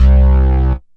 SYNTH BASS-1 0008.wav